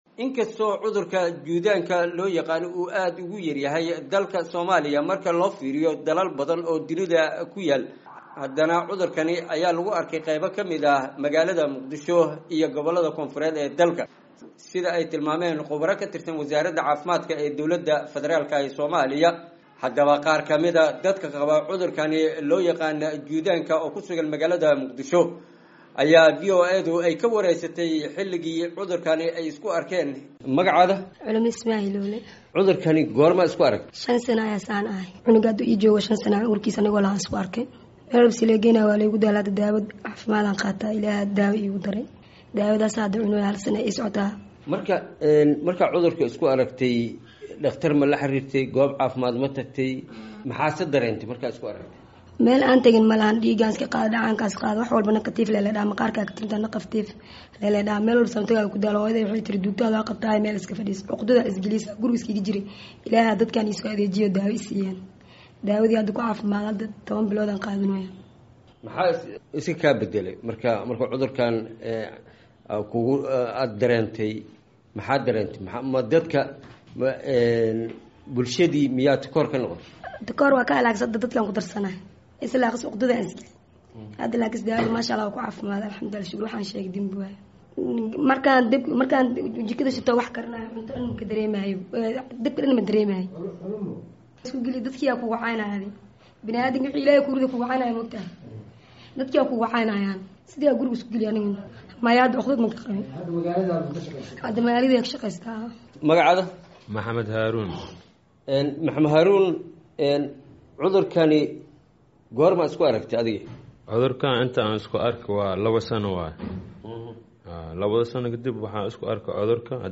Warbixin: Xaaladda Dadka Juudaanka Qaba ee Soomaaliya